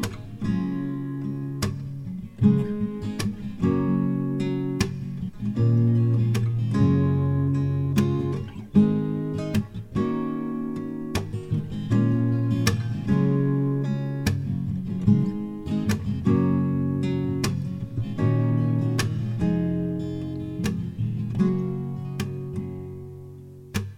Minus Main Guitar Pop (2010s) 4:47 Buy £1.50